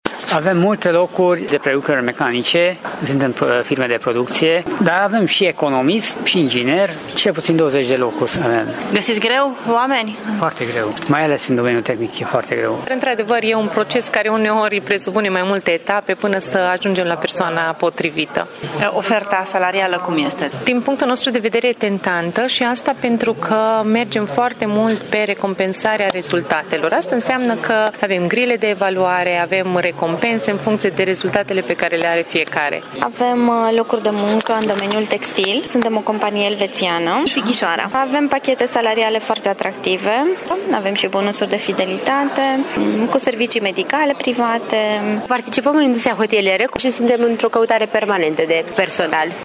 Peste 30 de companii din Târgu-Mureș, Reghin și Sighișoara s-au prezentat la târgul ce are loc astăzi, până la ora 18,00, la Teatrul Național.
Angajatorii mureșeni au venit cu pachete salariale atractive, mai ales că în ultimii ani găsesc din ce în ce mai greu oameni bine pregătiți: